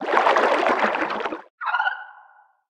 Sfx_creature_penguin_idlesea_B_02.ogg